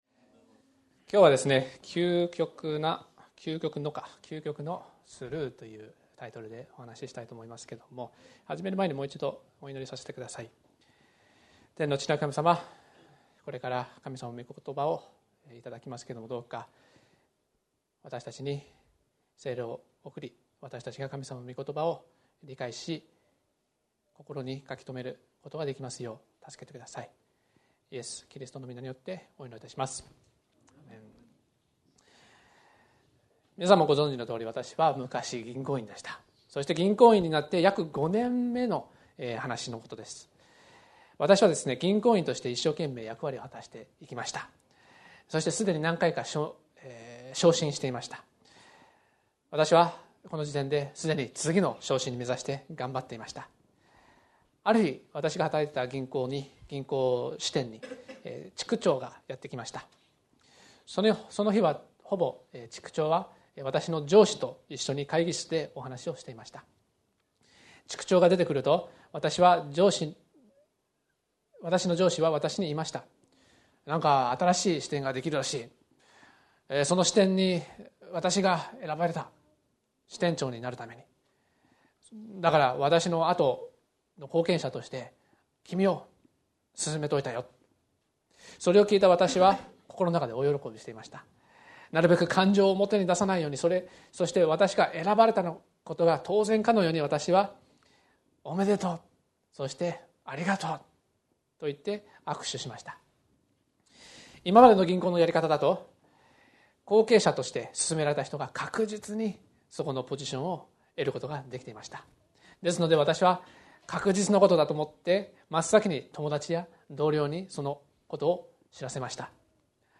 礼拝
聖書朗読 出エジプト記12章13節 あなたたちのいる家に塗った血は、あなたたちのしるしとなる。